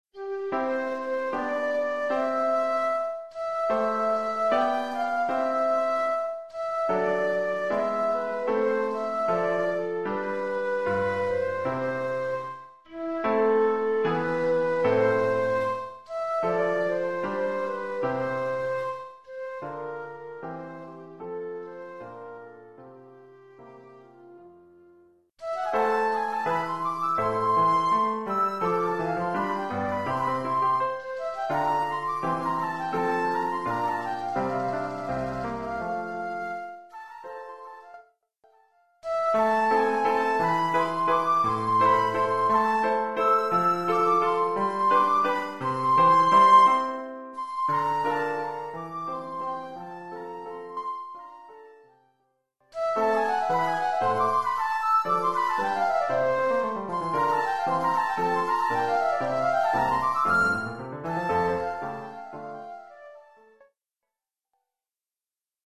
1 titre, ¨flûte et piano : conducteur et partie de flûte ut
Oeuvre pour flûte avec accompagnement de piano.
Oeuvre pour flûte avec
accompagnement de piano.